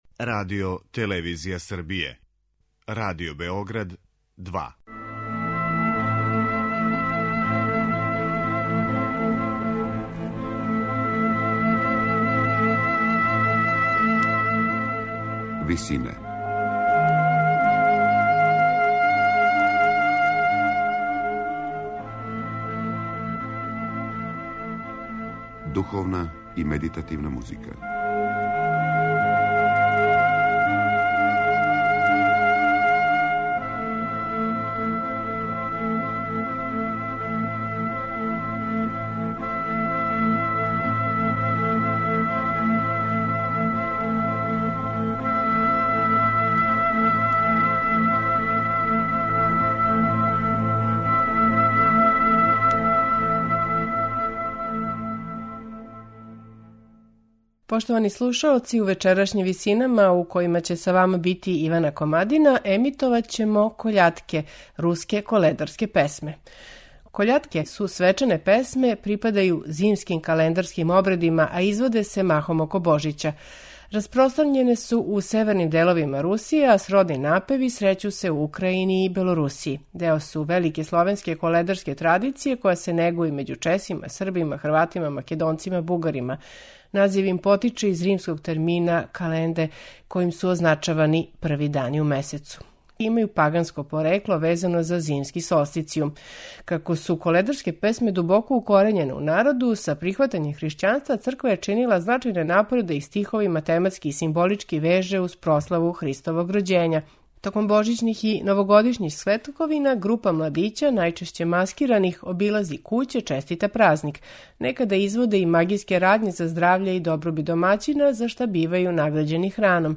Чућемо шест руских коледарских песама
Њихова песма по правилу је праћена инструментима, као и повицима "Певајте, радујте се" и "Запевајте, хришћани".